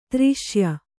♪ triṣya